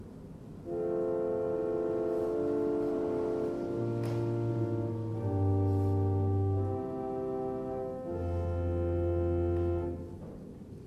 Klangprobe Salicional 8' - M2